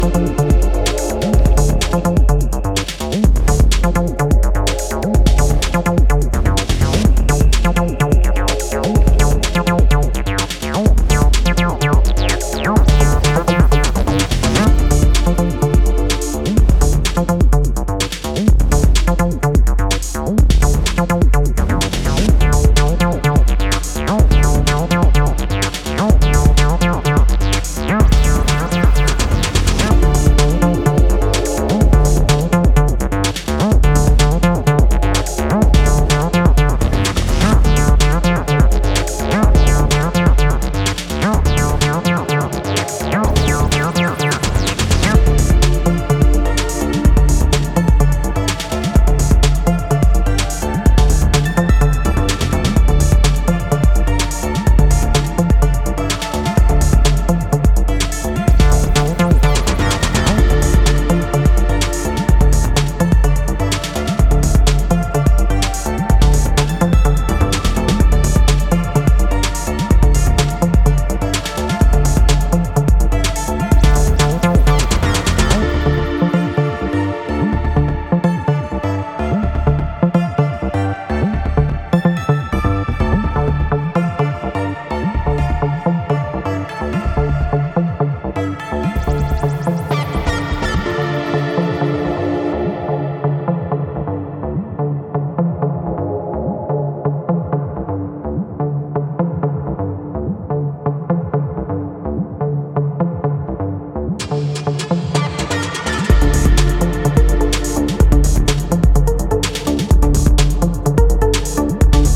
electronic music producer